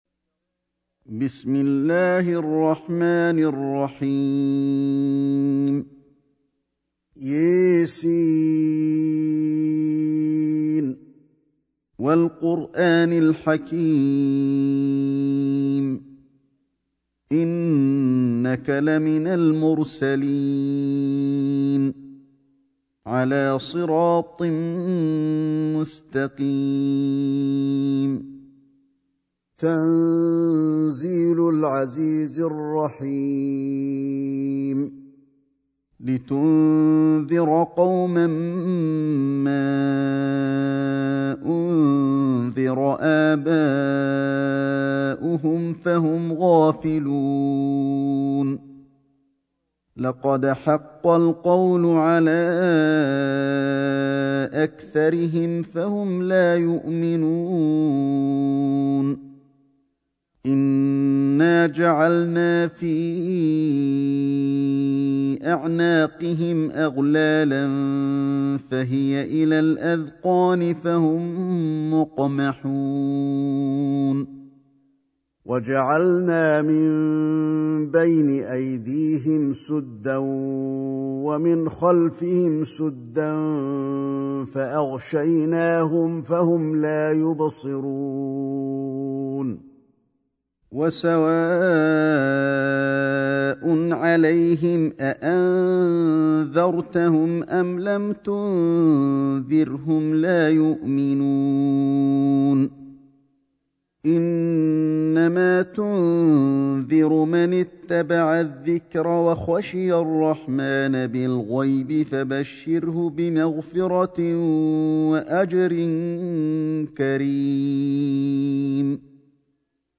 سورة يس > مصحف الشيخ علي الحذيفي ( رواية شعبة عن عاصم ) > المصحف - تلاوات الحرمين